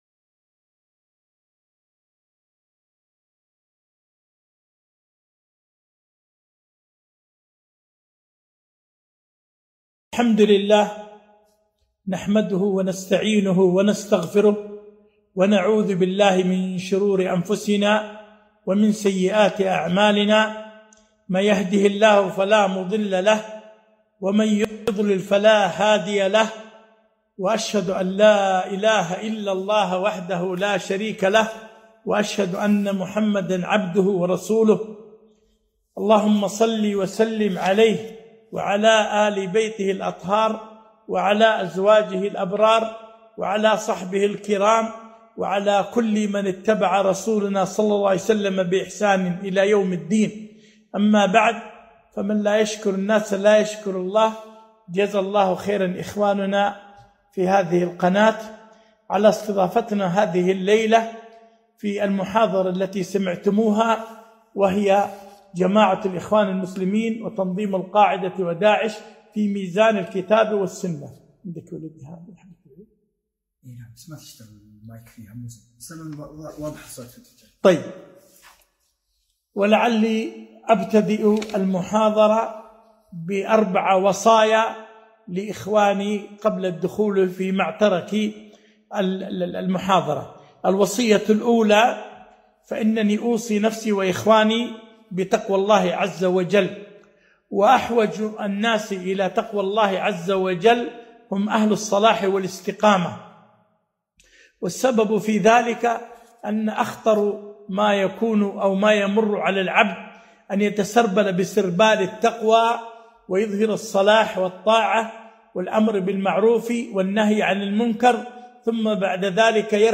محاضرة - تنظيمات الإرهابية في ميزان الكتاب والسنة